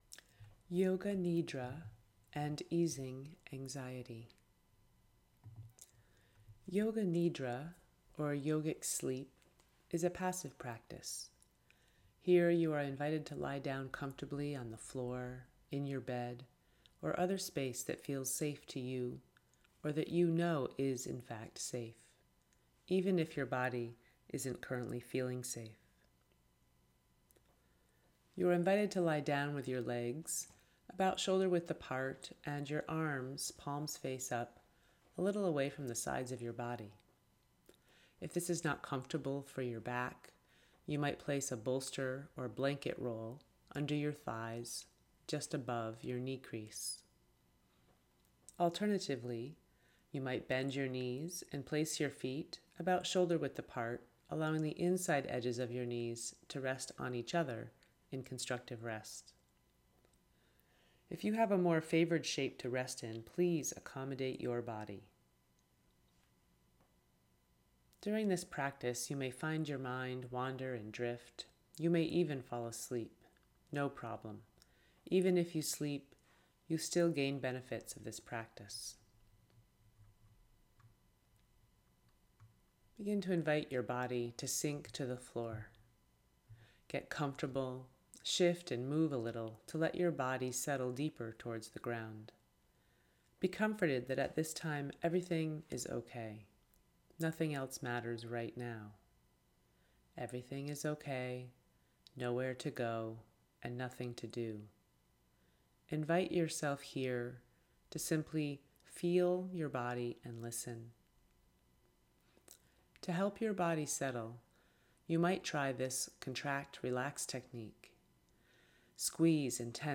Resource: Yoga Nidra Meditation Yoga Nidra is yogic sleep. This is a passive practice, where you lie down, get comfortable and simply listen as you are led on an inner journey.